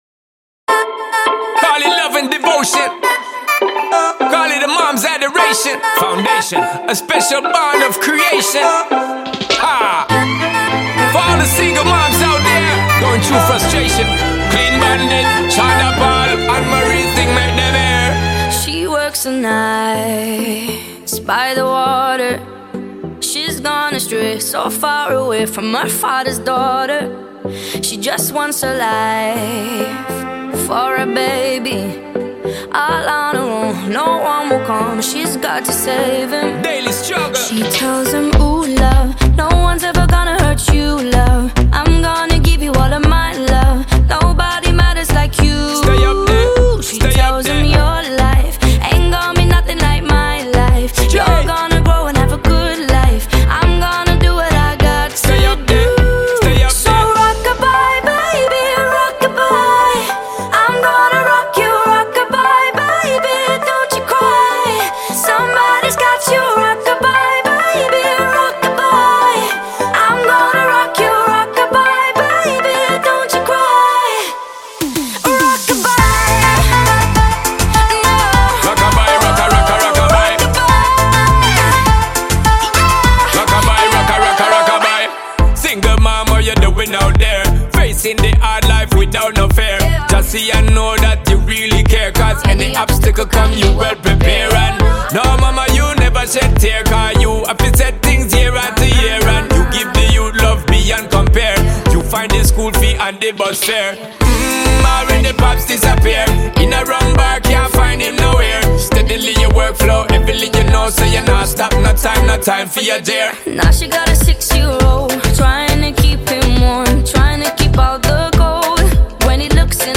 dance-pop